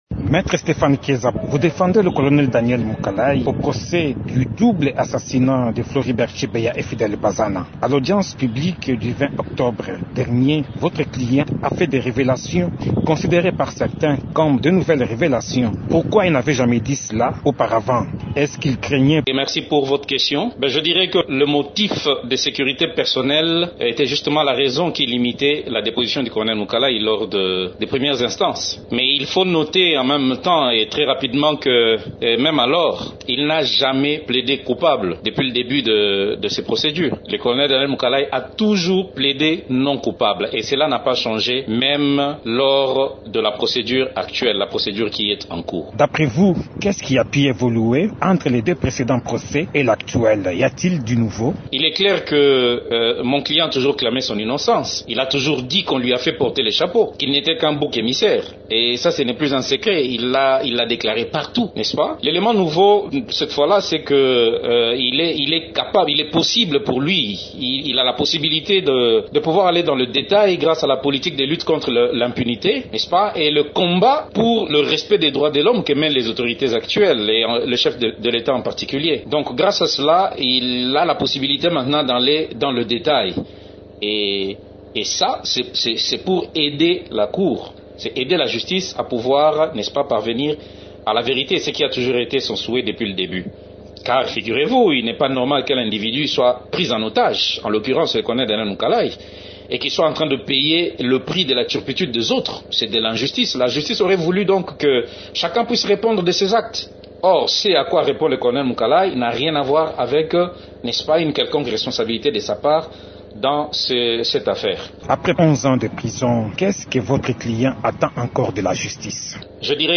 Invité de Radio Okapi, il réclame la libération de son client, à l’issue de ce procès qui se passe en appel devant la Haute cour militaire.